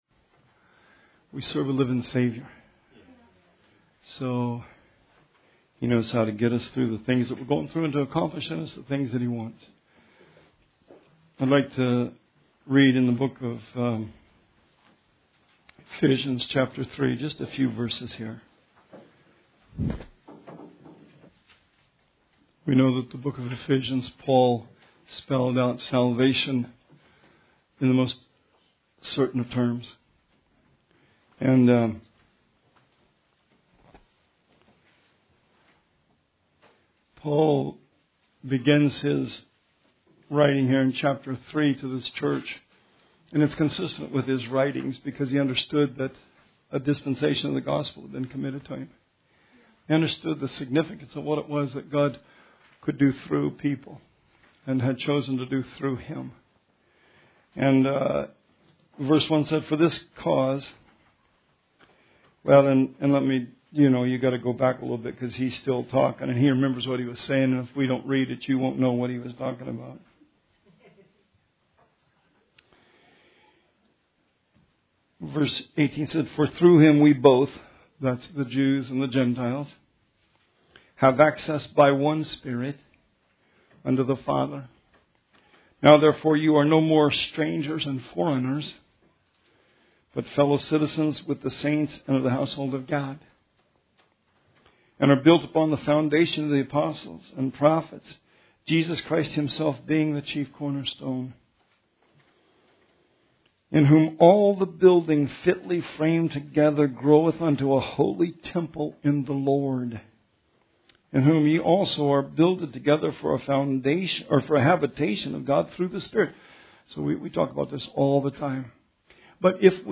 Sermon 2/24/19